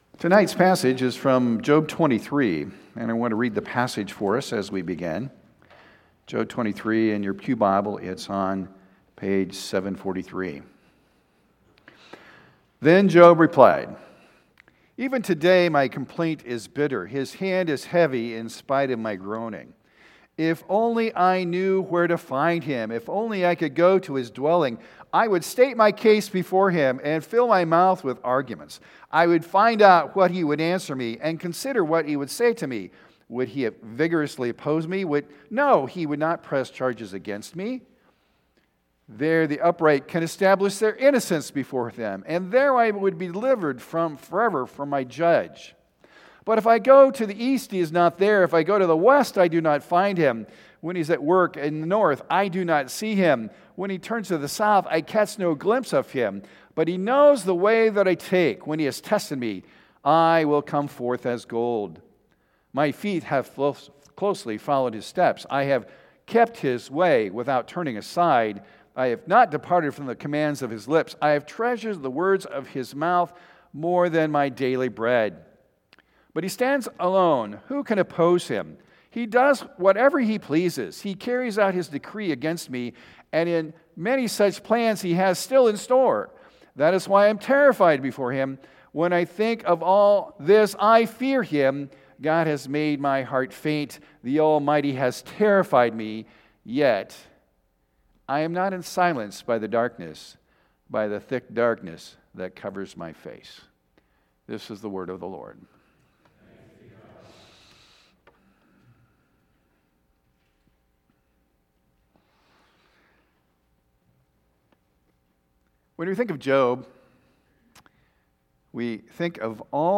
Sermon Recordings | Faith Community Christian Reformed Church